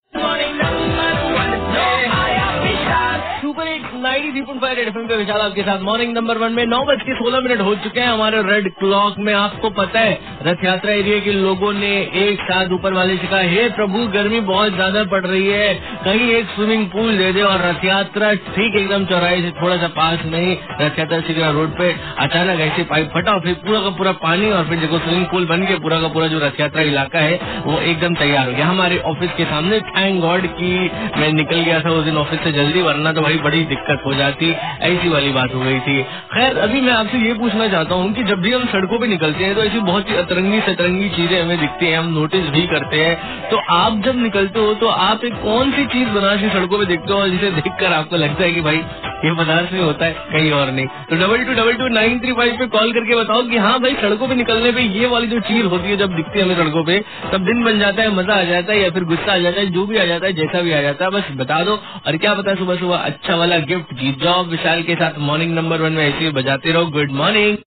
Rj talking about Banaras